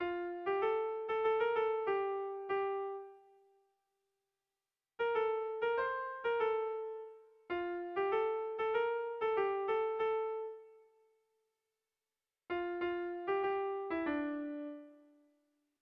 Erromantzea
Bizkaia < Basque Country
Neurrian baditu gorabehera batzuk.